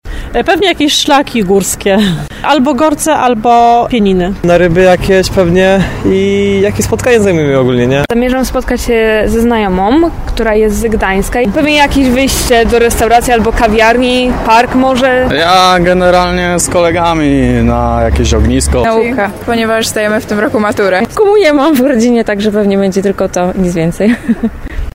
Pytamy tarnowian o ich plany na majówkę